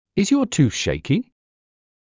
ｲｽﾞ ﾕｱ ﾄｩｰｽ ｼｪｲｷｰ